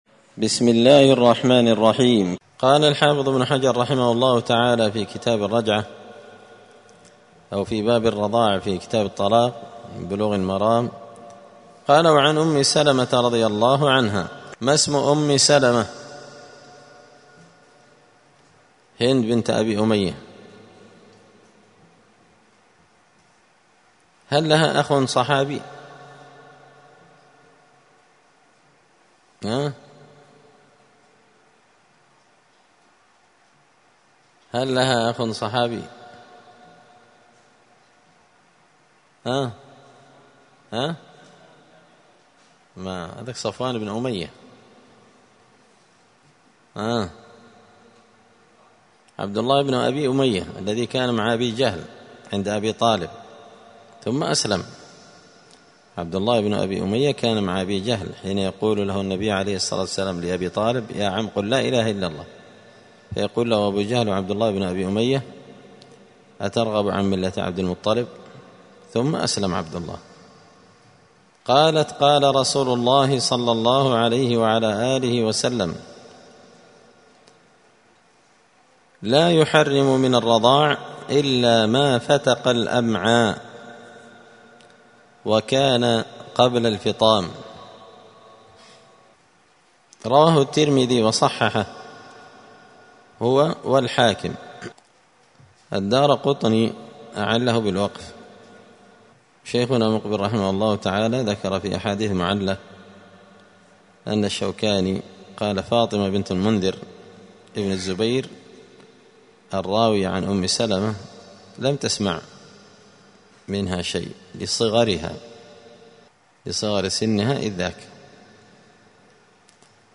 *الدرس الثالث والثلاثون (33) {تابع لباب الرضاع}*